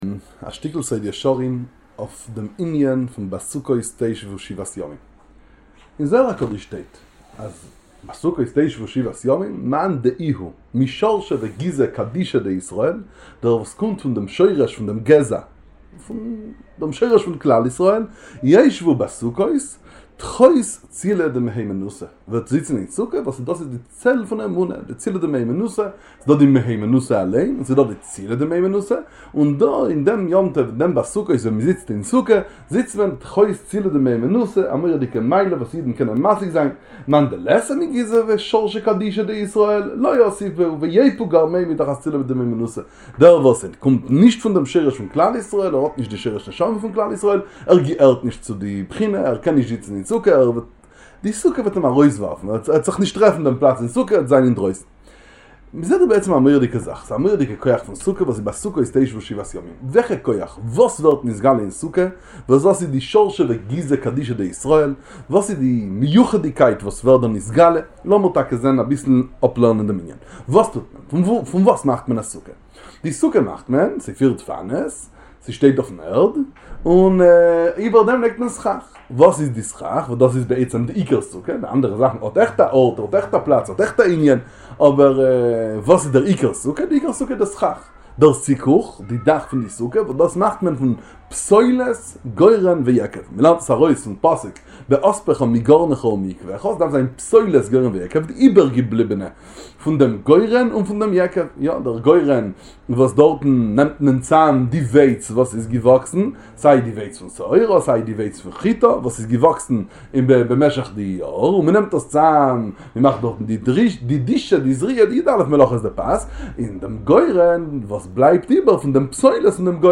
שיעור בספרי איז'ביצע ראדזין